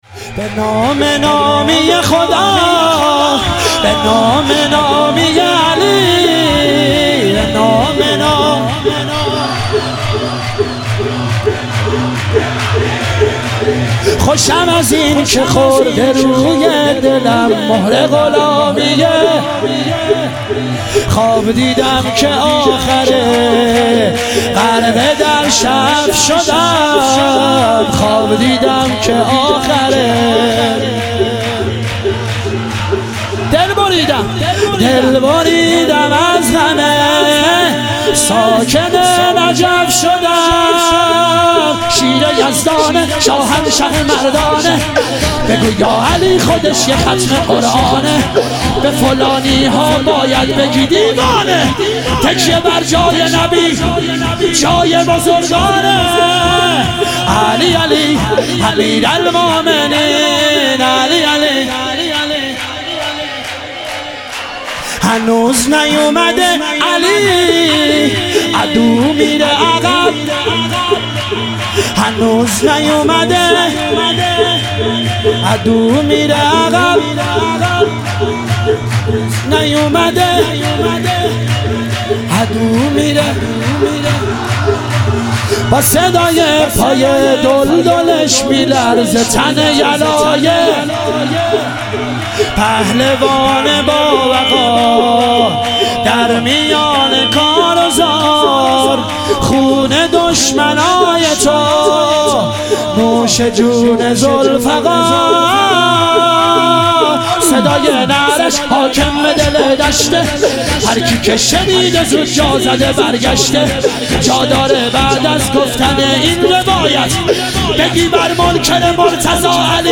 عید سعید غدیر خم - شور